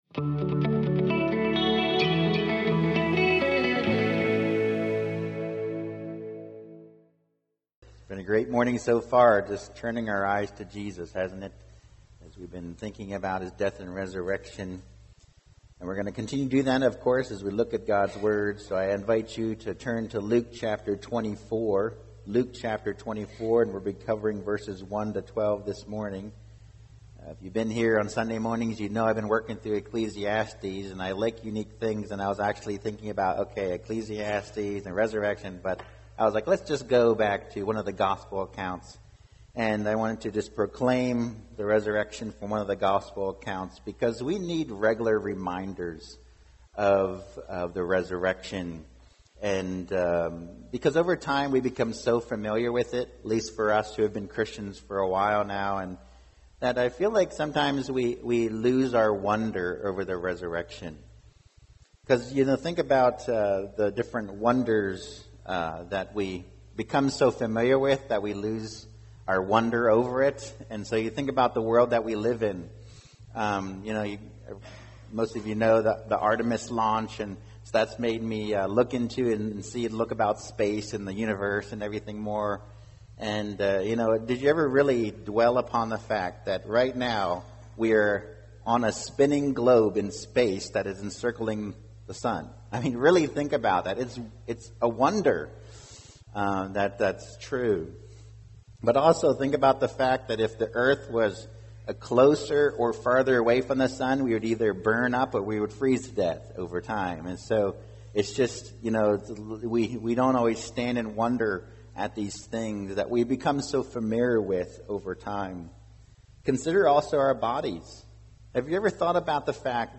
Non-Series Sermon Passage: Luke 24:1-12 Download Files Notes « Are My Efforts in Vain?